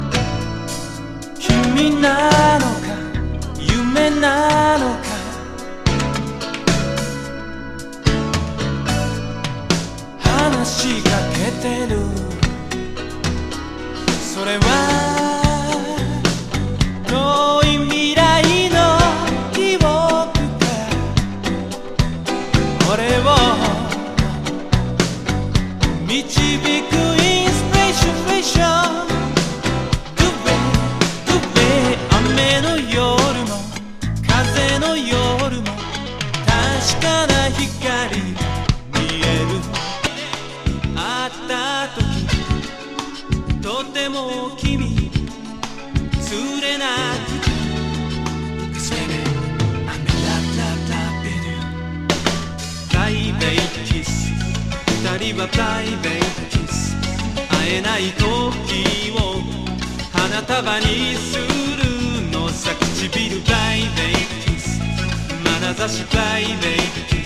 アーバン・ソウル歌謡